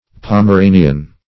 Pomeranian \Pom`e*ra"ni*an\, a.